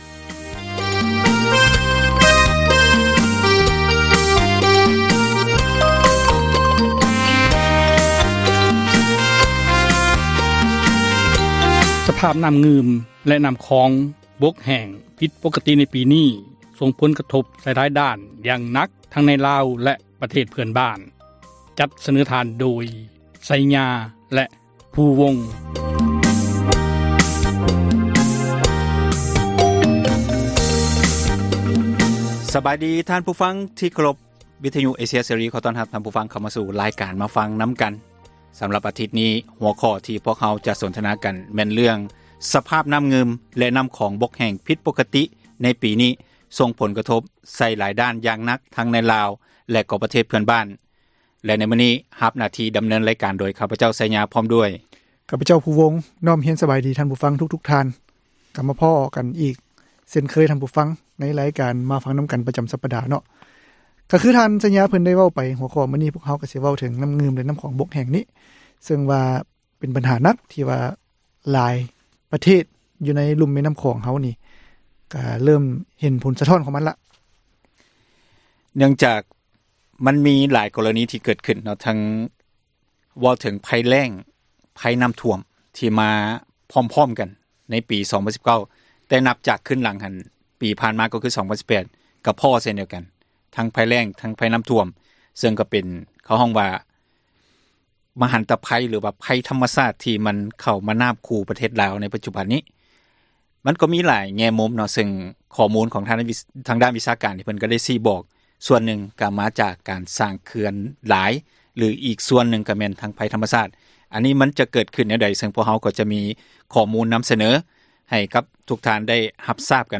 "ມາຟັງນຳກັນ" ແມ່ນຣາຍການສົນທະນາ ບັນຫາສັງຄົມ ທີ່ຕ້ອງການ ພາກສ່ວນກ່ຽວຂ້ອງ ເອົາໃຈໃສ່ແກ້ໄຂ,